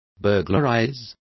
Also find out how escalar is pronounced correctly.